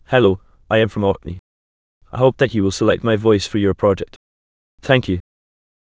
samples/p260.wav · voices/VCTK_European_English_Males at 277d85082b77c4cd9b74d0dcb26c7aeb4277b710